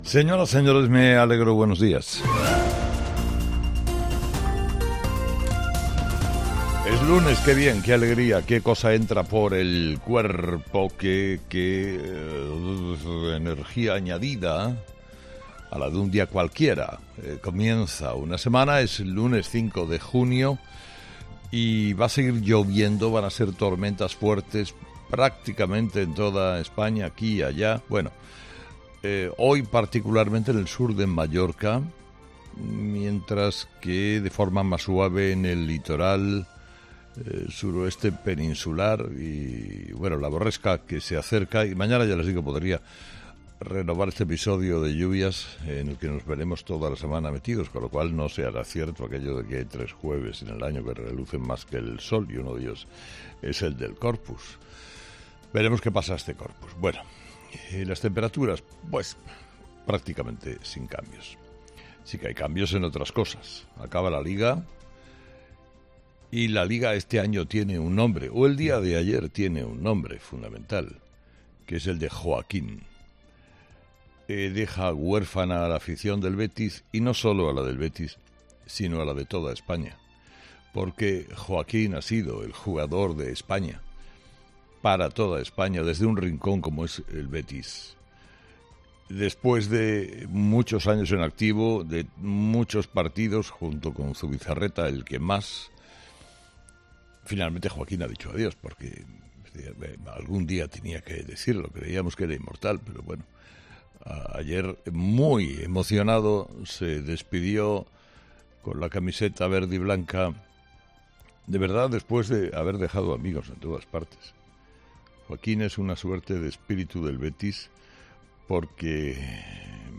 Escucha el análisis de Carlos Herrera a las 06:00 horas en Herrera en COPE este lunes 5 de junio de 2023